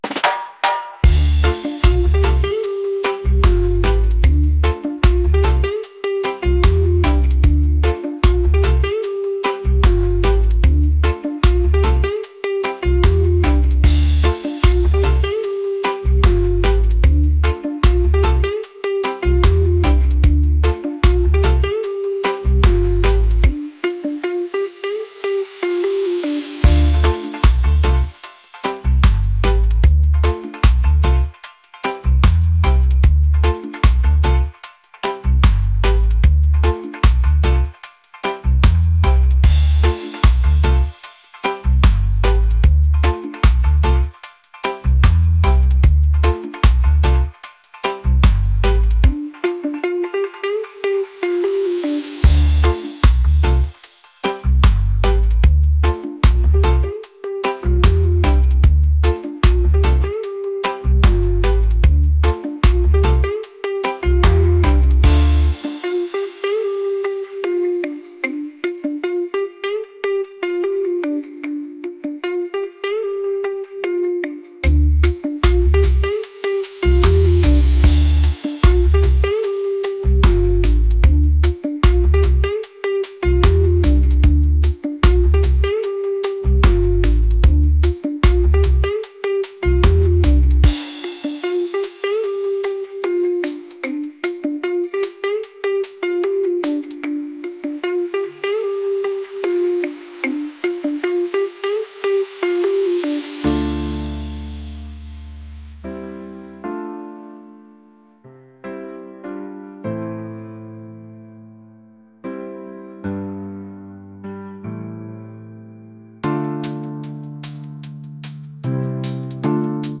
reggae | lofi & chill beats